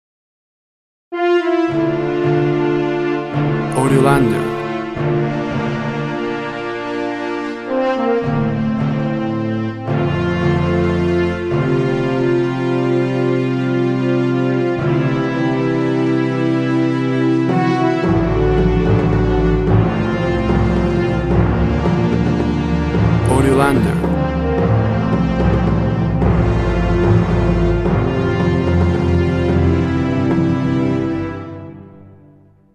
WAV Sample Rate 16-Bit Stereo, 44.1 kHz
Tempo (BPM) 110